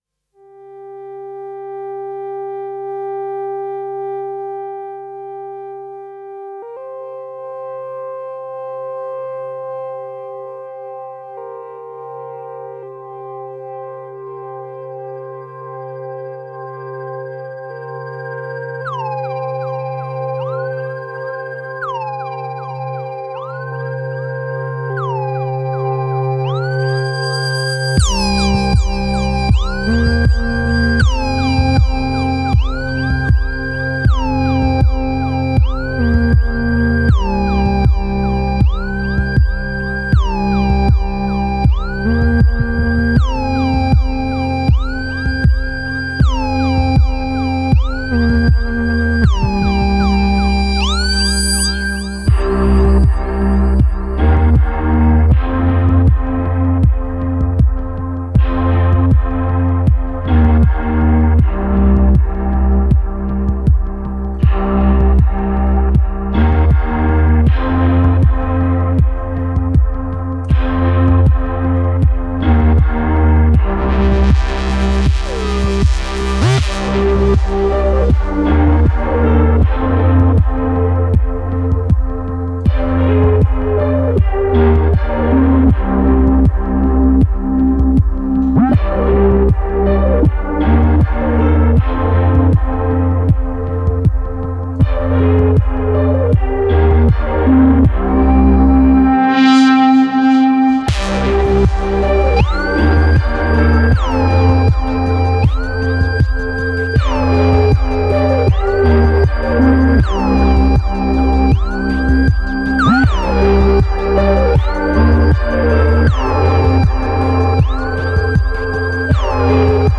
Otherworldly sounds refract and oscillate.